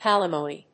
音節pal・i・mo・ny 発音記号・読み方
/pˈæləmòʊni(米国英語), pˈælɪməni(英国英語)/